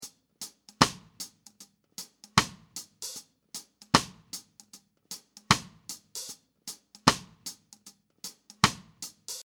Try hi hat swing with controlled open/close hi hat accents like this.
Traffic-Light-Mind-hi-hat-feel.mp3